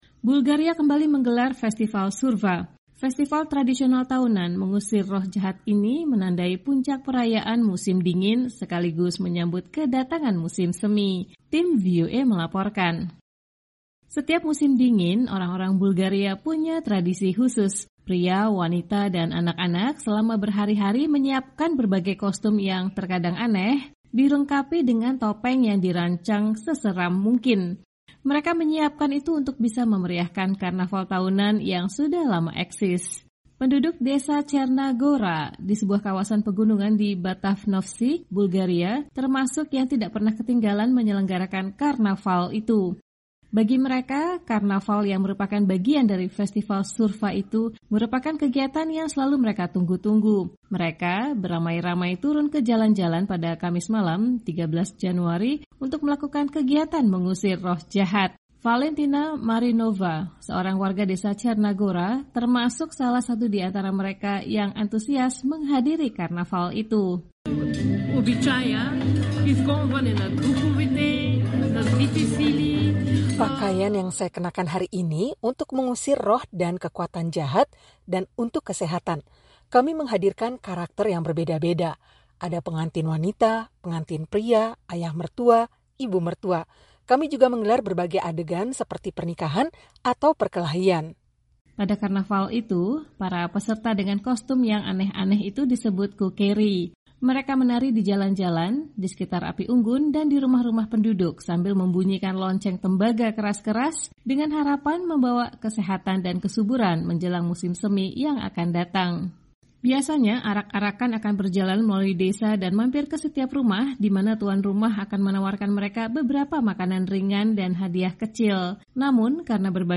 Bulgaria kembali menggelar Festival Surva. Festival tradisional tahunan mengusir roh jahat ini menandai puncak perayaan musim dingin, sekaligus menyambut kedatangan musim semi. Tim VOA melaporkan.